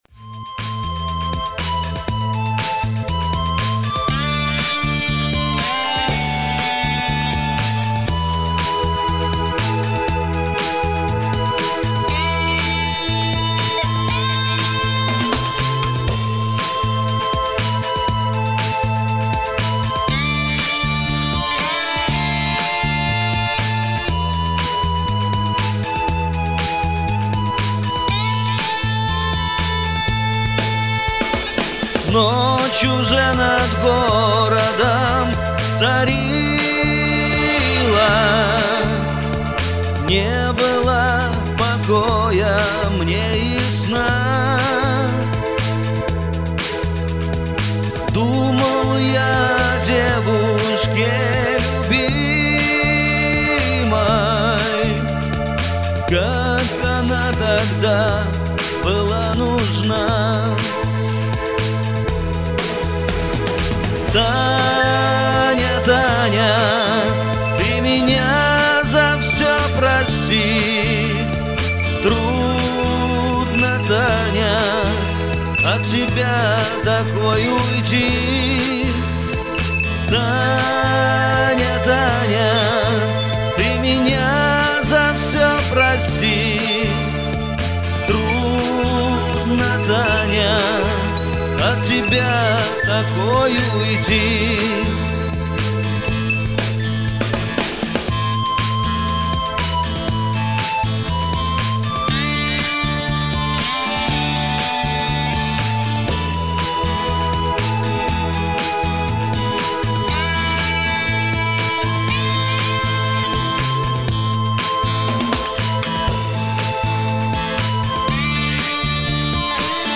перепевка дворовой песенки